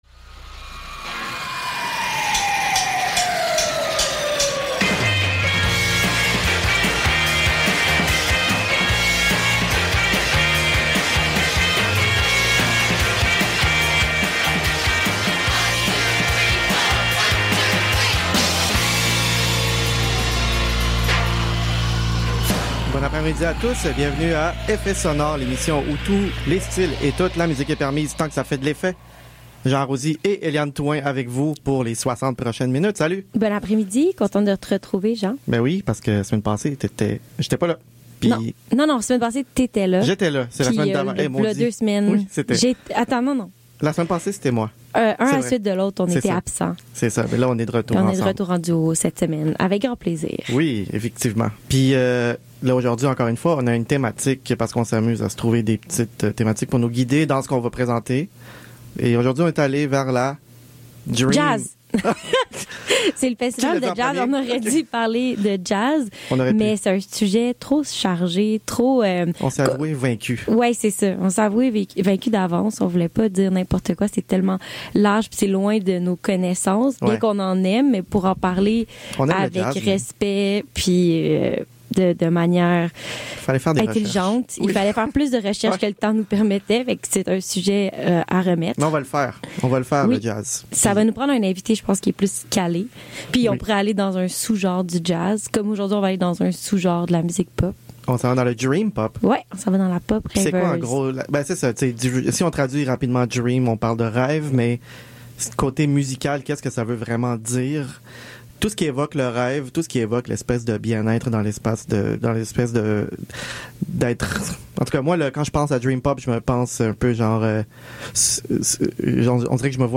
C\'est avec la tête dans les nuages qu\'on se transporte dans la musique dreamy dans cet épisode très dream pop!